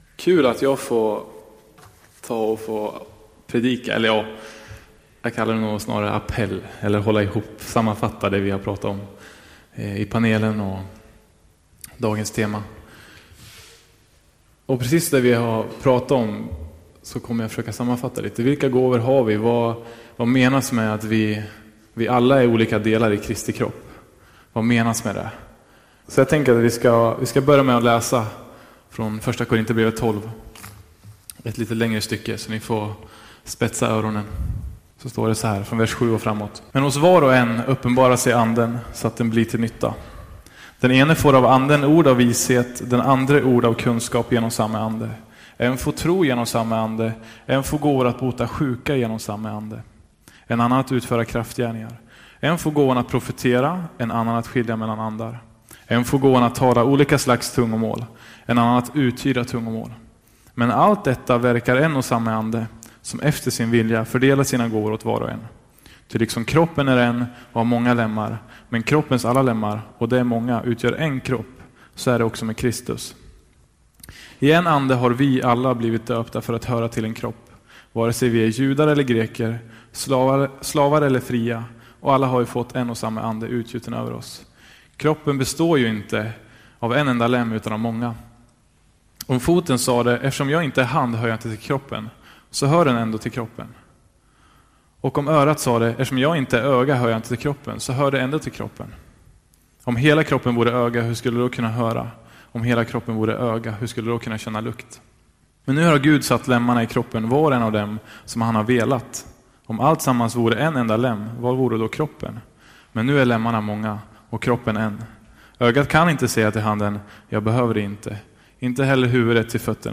Predikningar